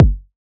edm-kick-86.wav